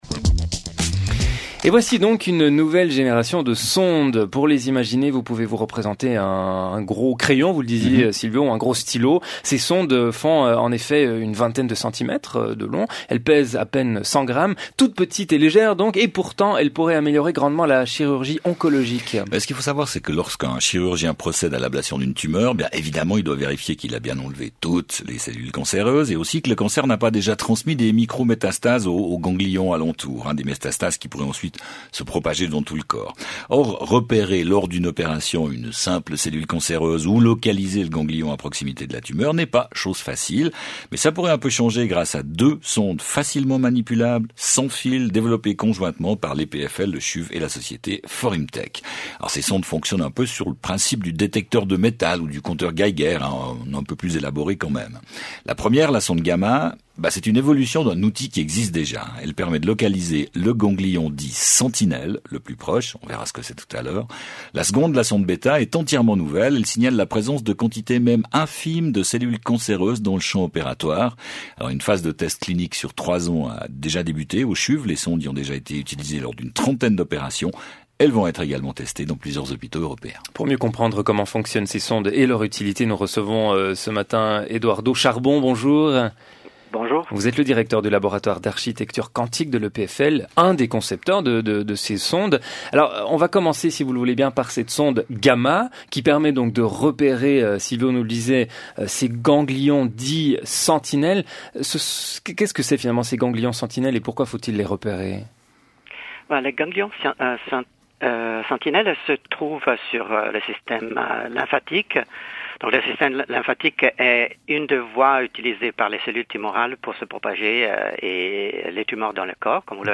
Un nouvel outil pour la traque aux tumeurs” Interview